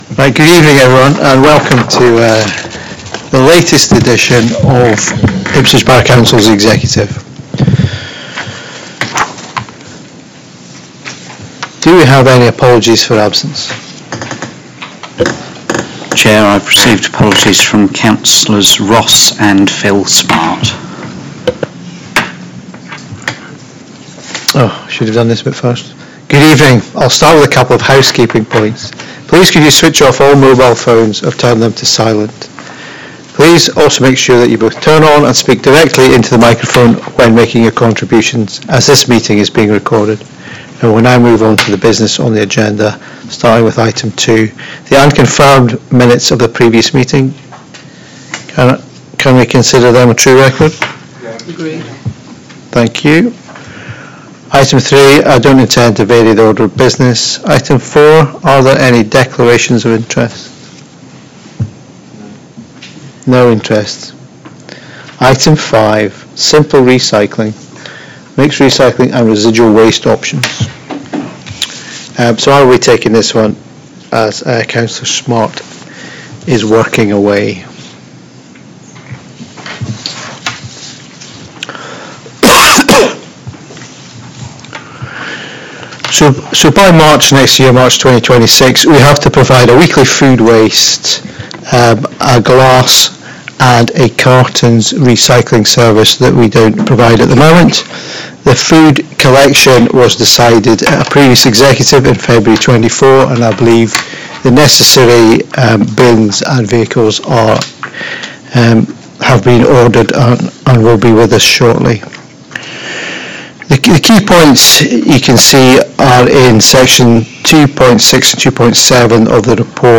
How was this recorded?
Agenda for Executive on Tuesday 8th April 2025, 6.00 pm